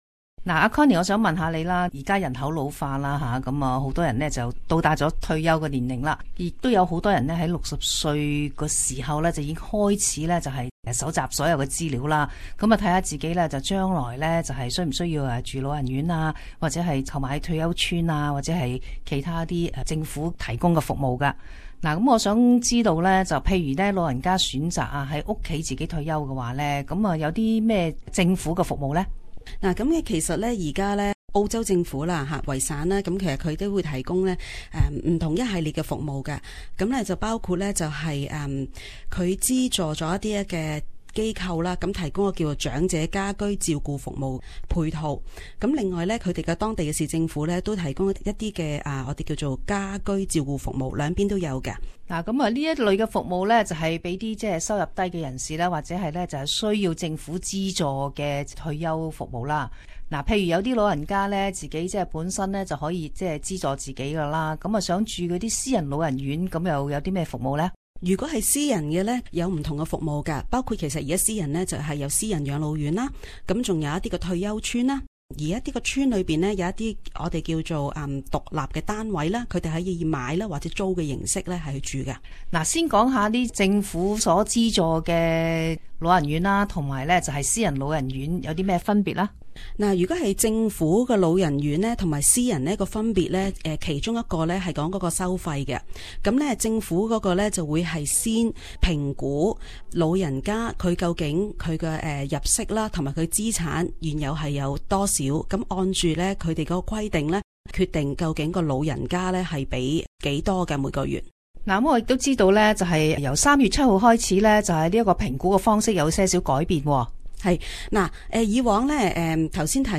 Community Interview - Home Support & Nursing Home